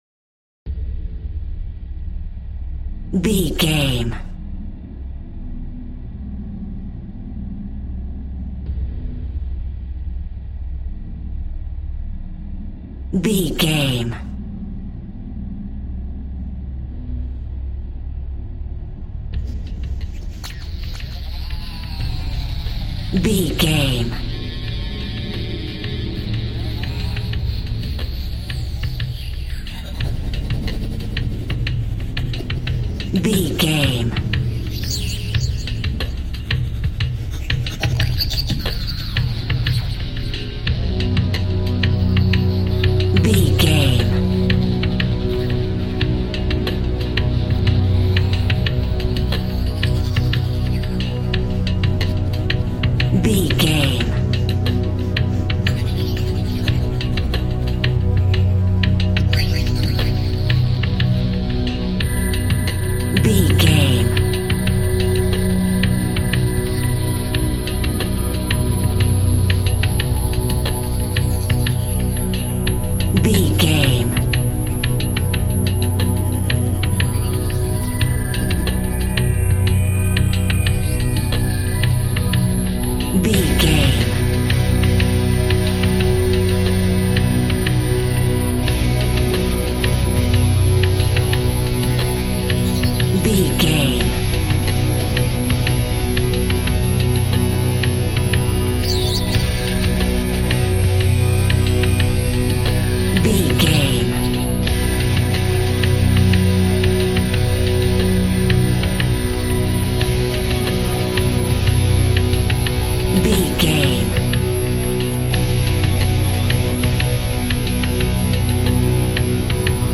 Ionian/Major
suspense
piano
synthesiser